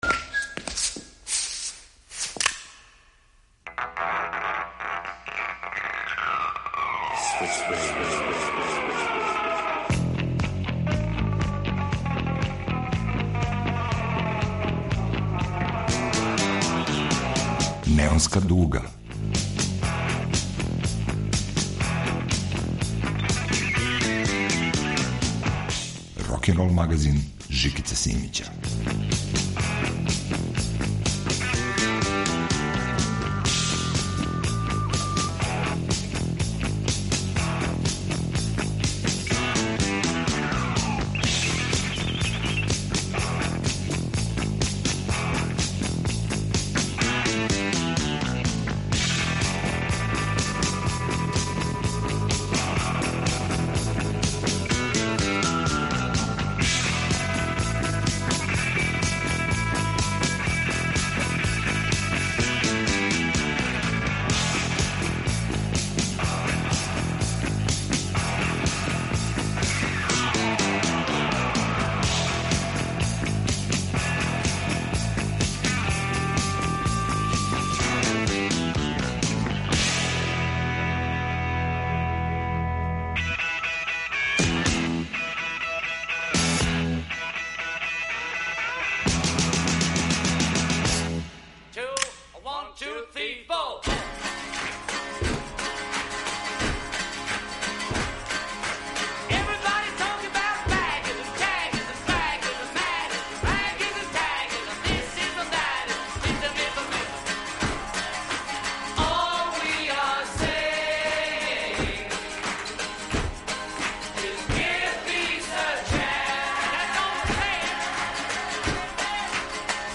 Кроз двадесетак песама које су на репертоару најновијег издања Неонске дуге трага се за одговором.